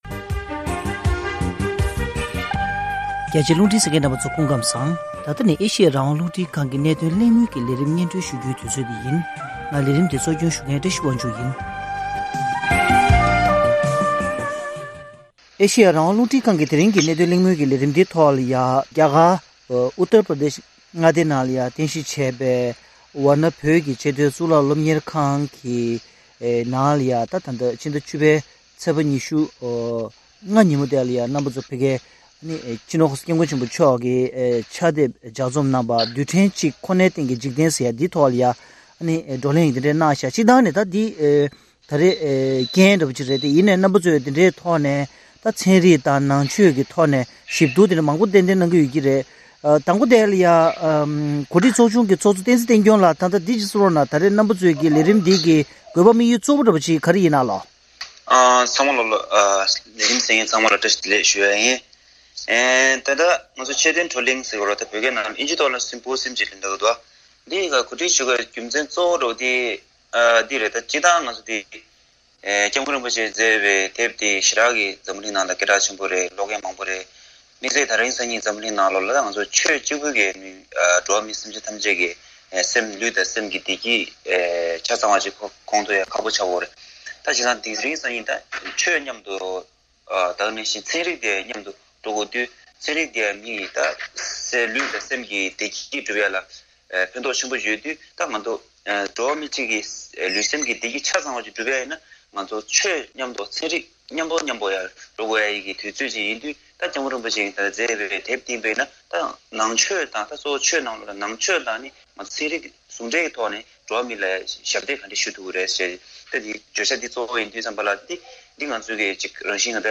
༄༅།།གནད་དོན་གླེང་མོལ་གྱི་ལས་རིམ་ནང་།